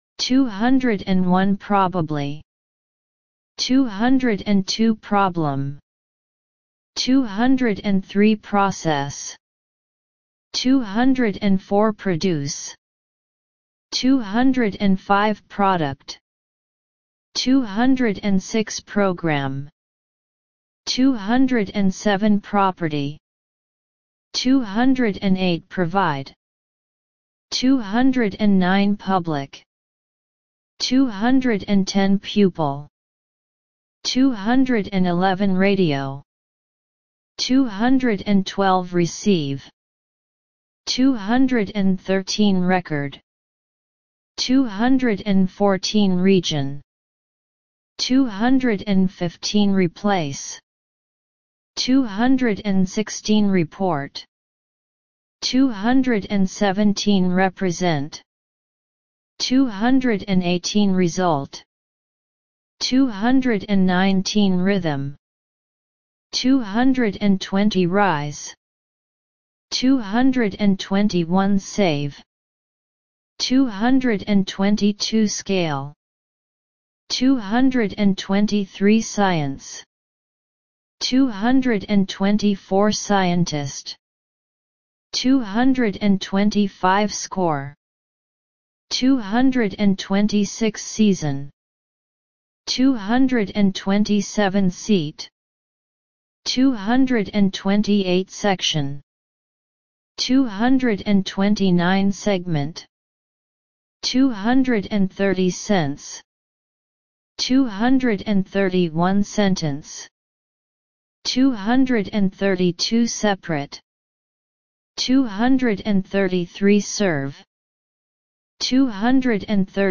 201 – 250 Listen and Repeat